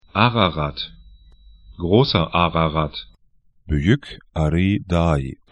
Grosser Ararat   'a:rarat
'gro:sɐ 'a:rarat   Büyük Ağrı dağı by'jʏk a:'ri: 'da:i tr Berg / mountain 39°42'N, 44°18'E